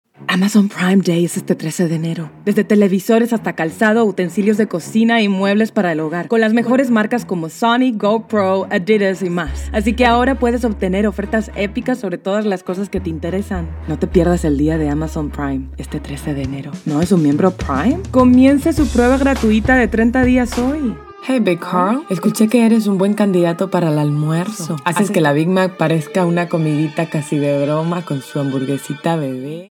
Versatile, naturally mature, essentially deep, inspiring, exciting, creative, surprising voice!
Vocal age between 15 and 50 years.
MEX SPA COMMERCIAL 2.mp3